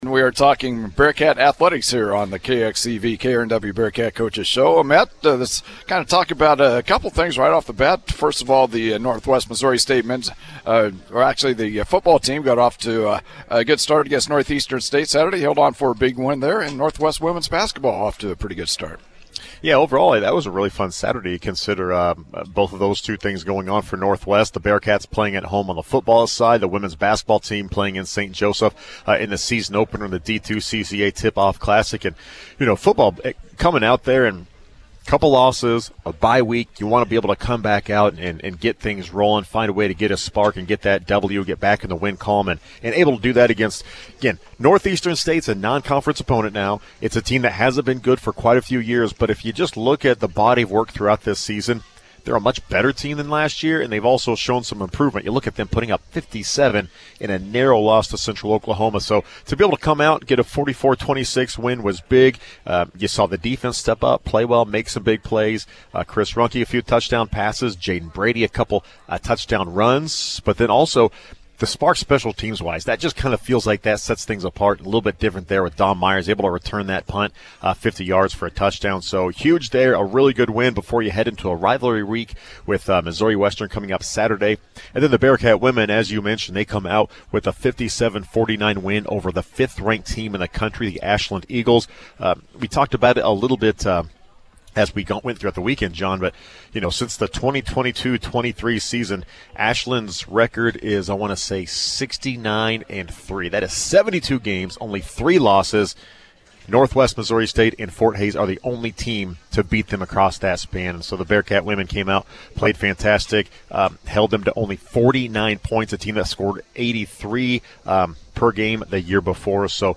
Local Sports